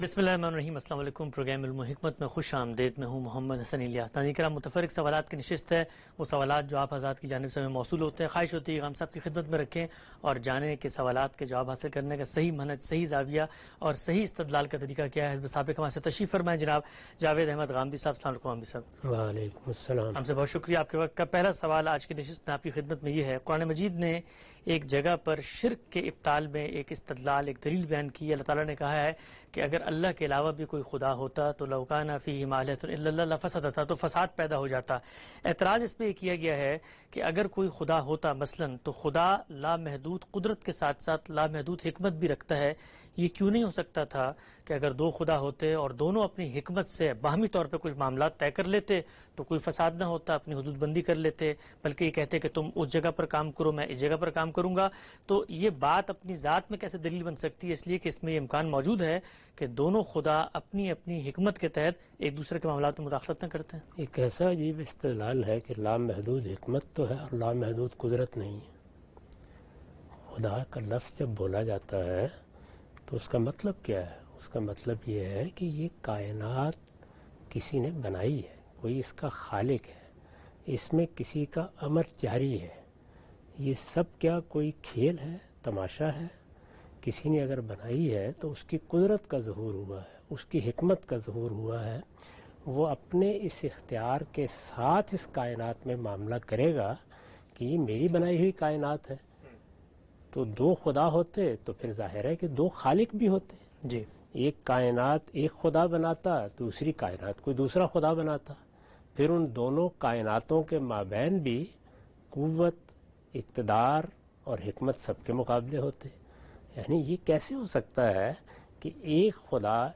In this program Javed Ahmad Ghamidi answers the questions of different topics in program "Ilm-o-Hikmat".